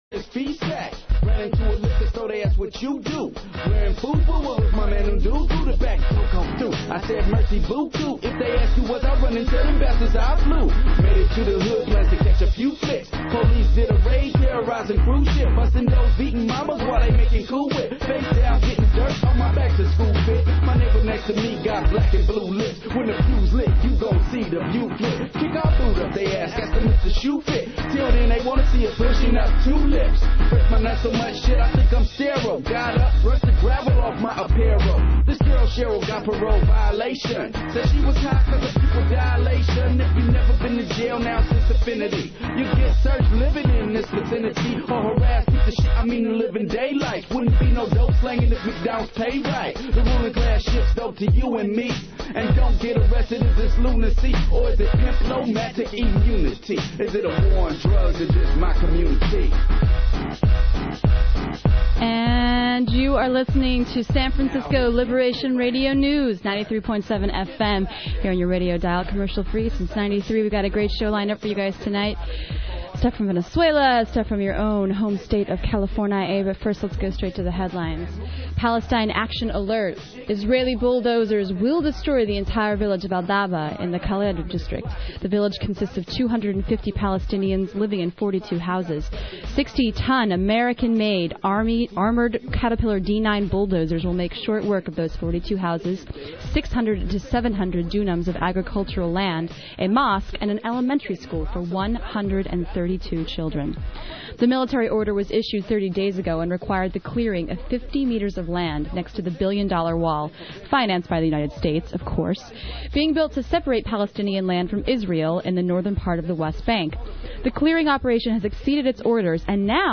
Headlines: Israeli pre-emptive strike against Palestinian village; Brazil Adds Enviro To Cabinet; Fighting Navy Use of Sonar; China Questions Death Penalty; Labour Warns Blair Against War on Iraq; Nun Goes to Jail to Defend Right to Protest * LA-INS Protest interviews/rally chants
(periodic translation throughout) * Venezuelan protest hip-hop